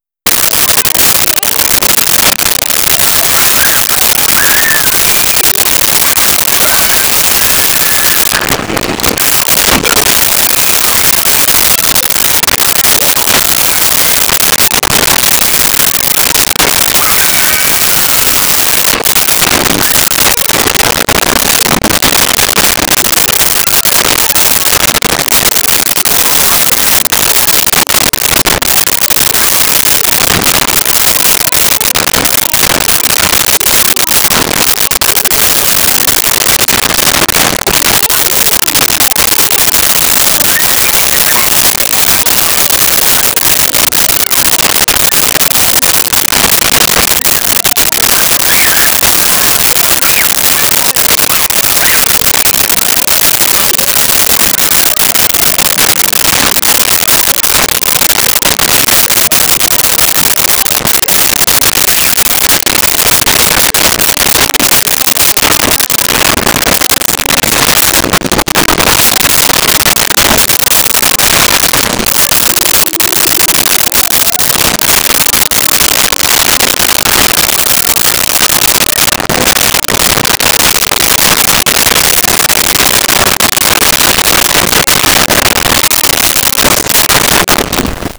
Playing Talking Children
Playing Talking Children.wav